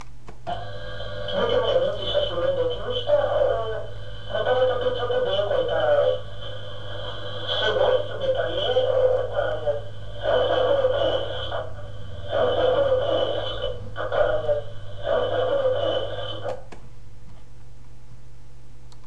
TRANSCOMUNICAZIONE STRUMENTALE
Le voci sono registrate al magnetofono, con il supporto di una radio.Per facilitarvi l'ascolto , con il ritorno del registratore faccio ripetere tre volte la stessa parola